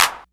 808-Clap18.wav